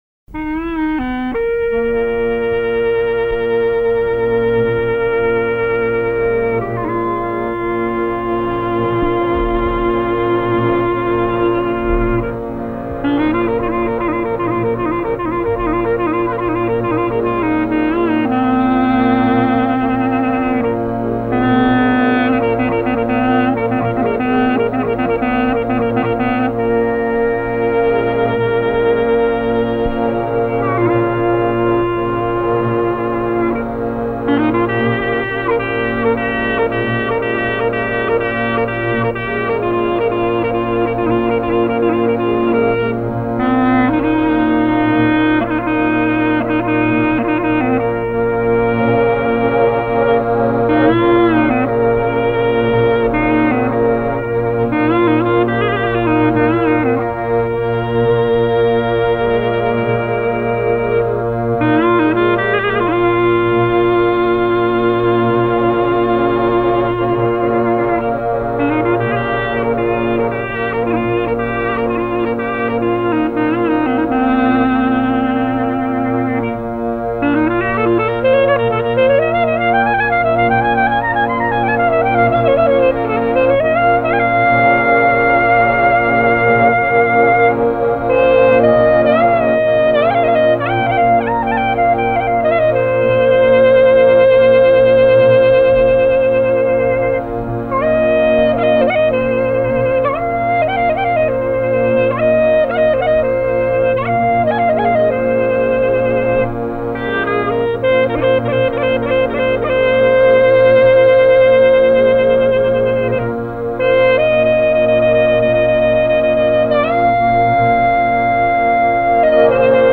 strukturoret si kaba tipike njëpjesëshe, me motiv vajtimor të Labërisë me kadencime të shpeshta në septimën minore.
Gërneta shfaqet e thjeshtë dhe e përmbajtur në shtjellimin e motivit vajtimor por plot me ëmbëlsi dhe qartësi të frazave melodike.
Tekstura e kabasë shtjellohet qetë dhe natyrshëm mbi një shtrat ritmik “ad libitum” kryesisht në regjistrin e poshtëm dhe të mesëm të gërnetës.